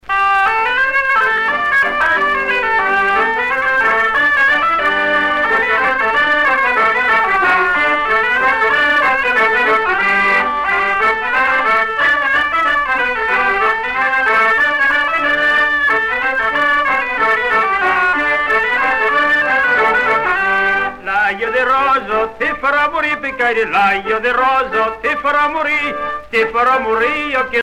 Chants brefs - A danser
danse : scottich trois pas
Pièce musicale éditée